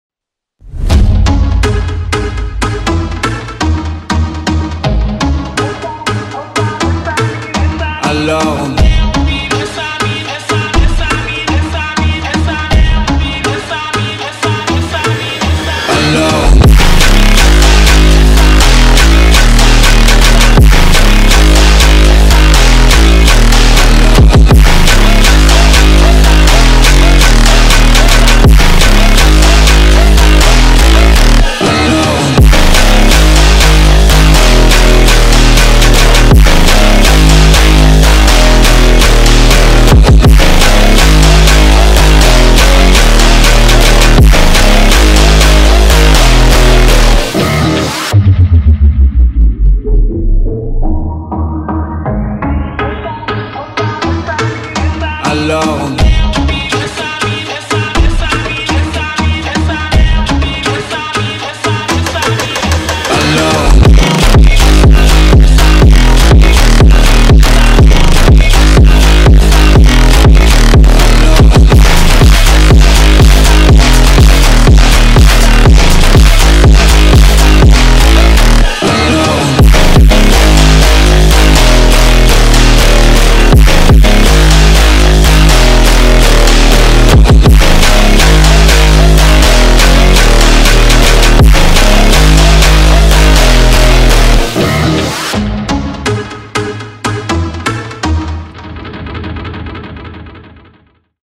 MKht2uYpgk8_ALORS-BRAZIL-PHONK-.mp3